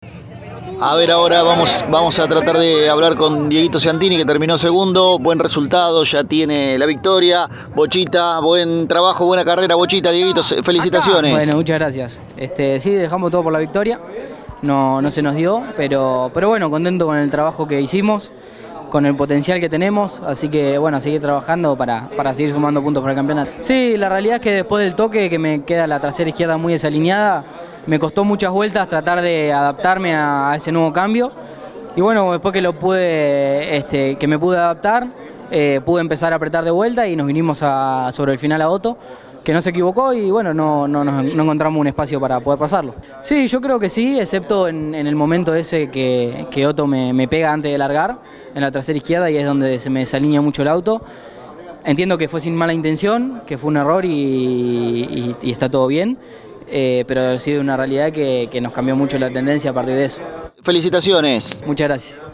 El balcarceño pasó por los micrófonos de Pole Position y habló del gran fin de semana que tuvo junto al JP Carrera, que le permite escalar en el campeonato regular del Turismo Carretera.